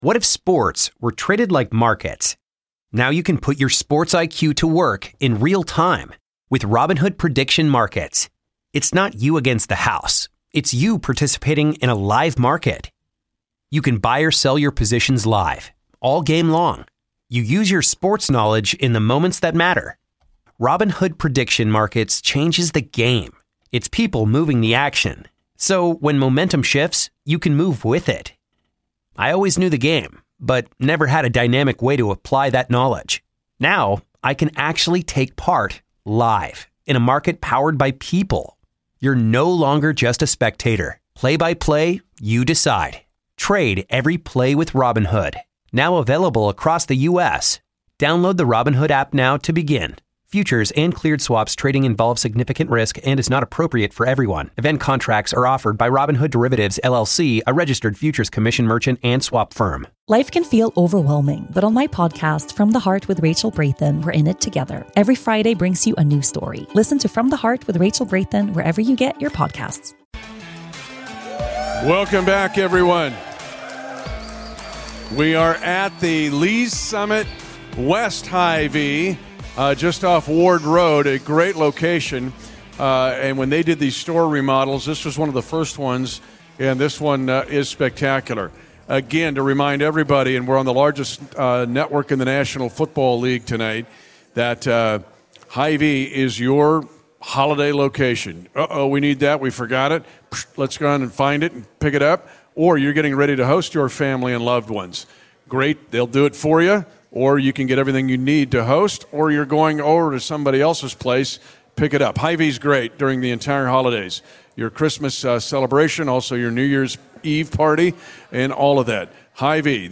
exclusive 1-1 interview following a historic day for the organization as they announce that they are moving to Kansas